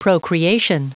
Prononciation du mot procreation en anglais (fichier audio)
Prononciation du mot : procreation